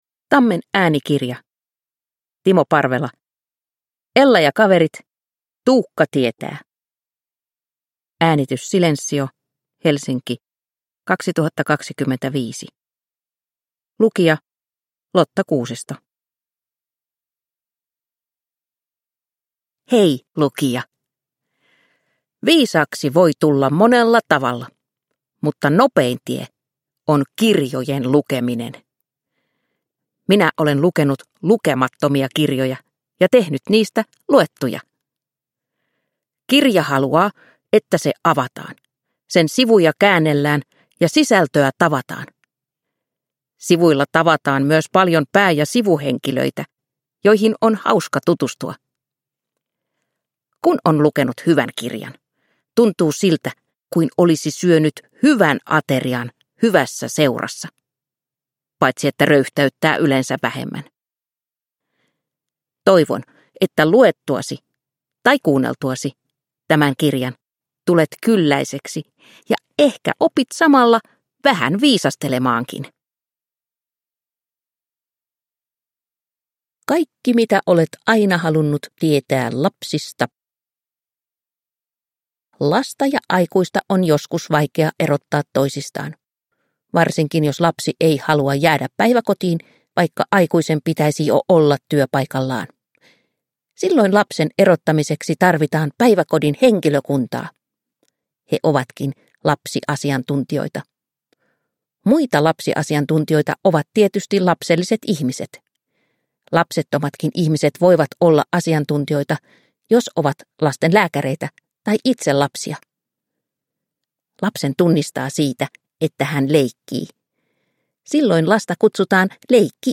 Ella ja kaverit. Tuukka tietää! – Ljudbok